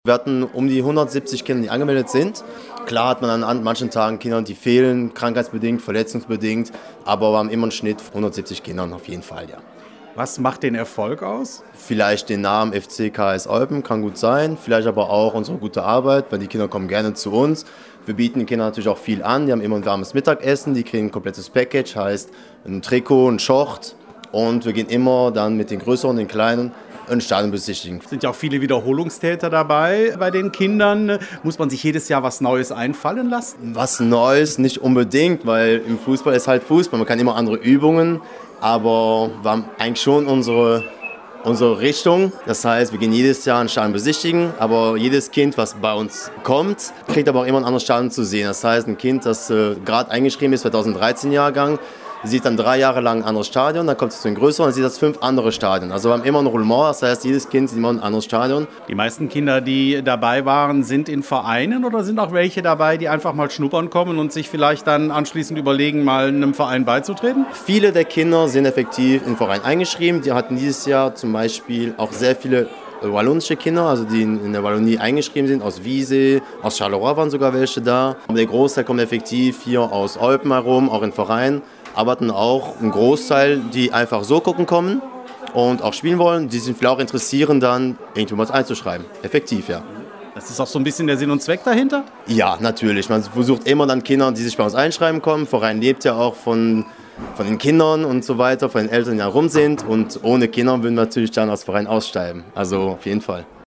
vor Ort